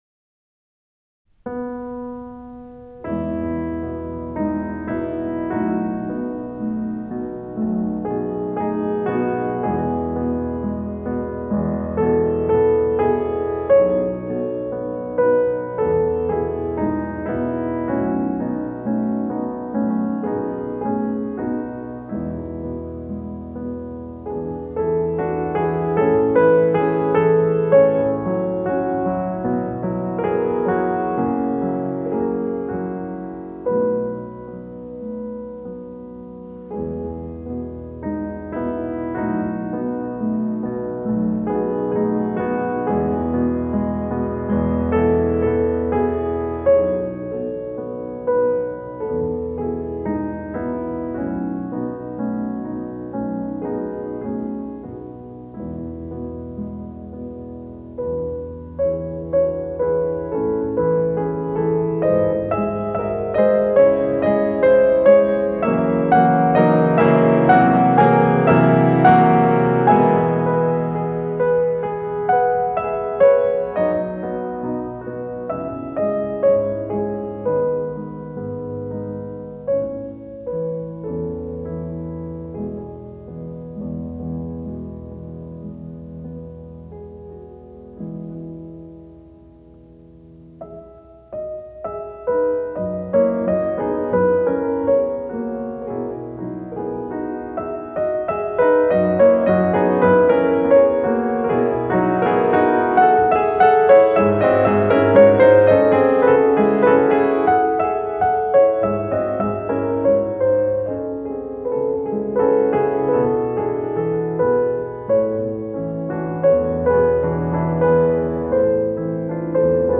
この曲が完成したとき、ショパン自らが「最も美しい旋律」という言葉を残したように、類い希なる美しさを持つ永遠のピアノ曲となっている。